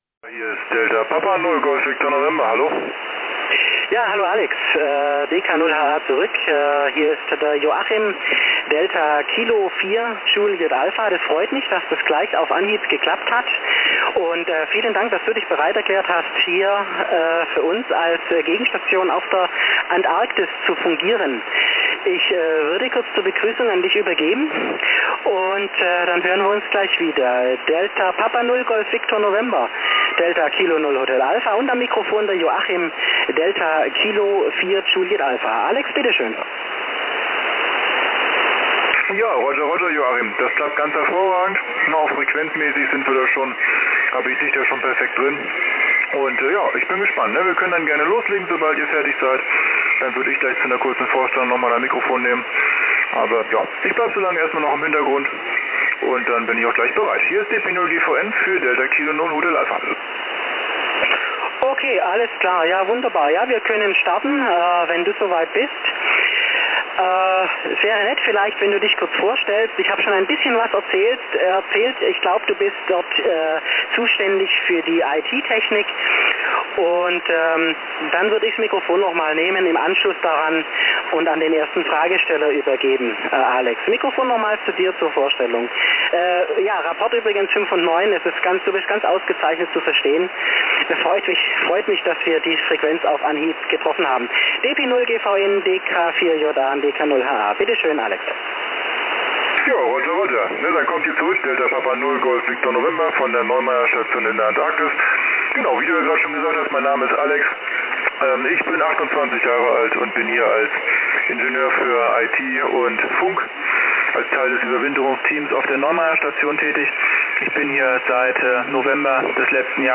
Funkkontakt mit der Neumayer III Station DP0GVN
Im Rahmen des „Tag der offenen Tür“ bei der Zukunftsakademie Heidenheim haben wir erfolgreich eine Funkverbindung mit der Neumayer III Forschungsstation in der Antarktis aufgebaut.